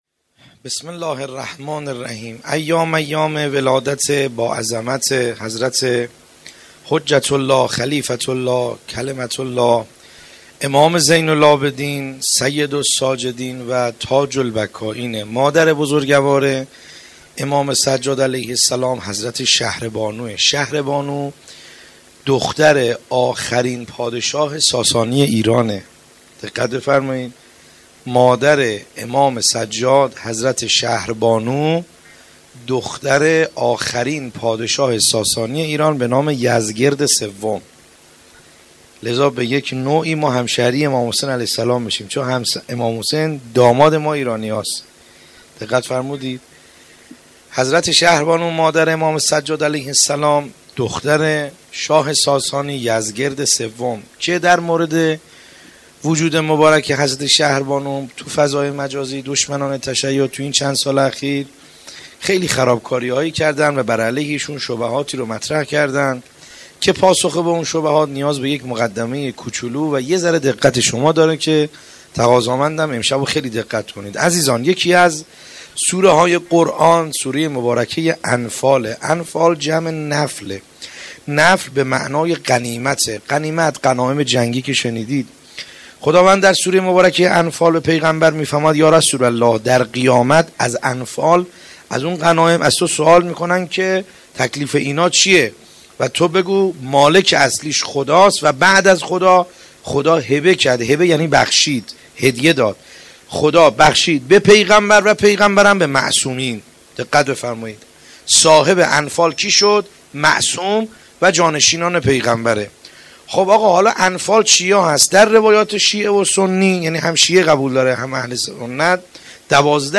سخنرانی
میلاد امام سجاد علیه السلام